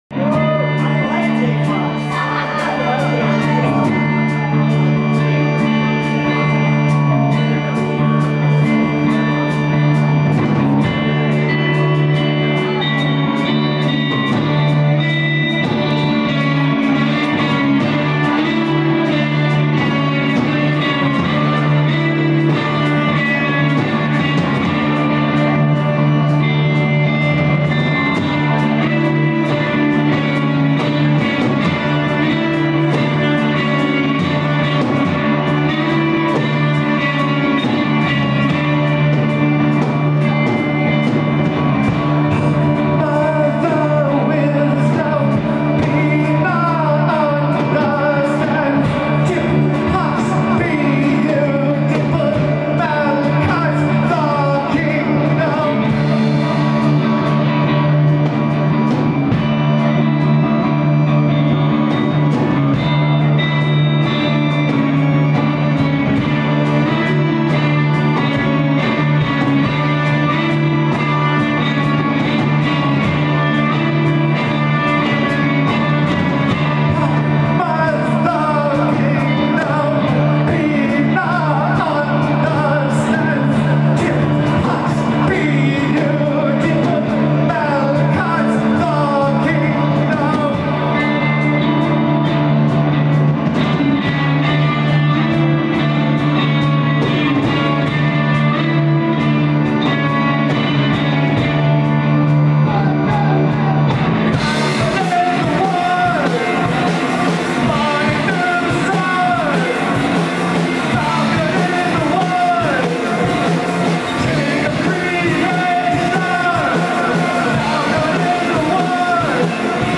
Live from Tooeys:2014